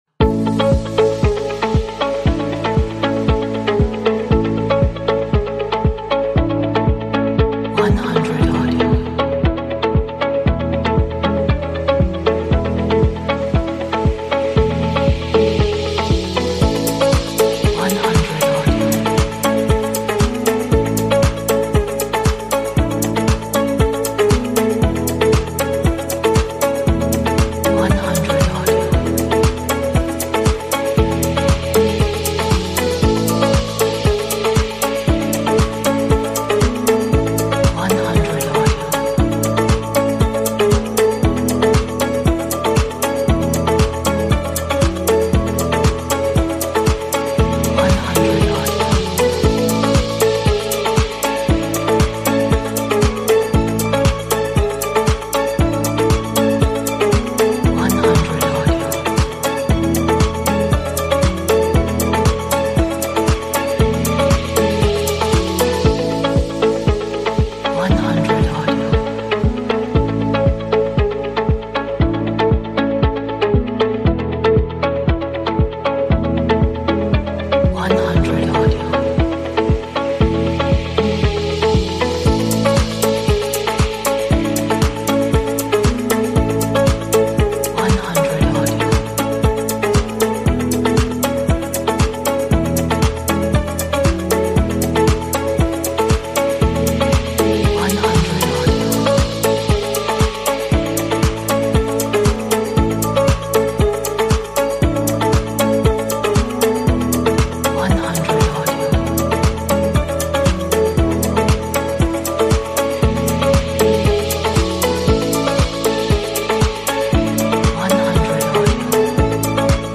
Atmospheric, dramatic, epic,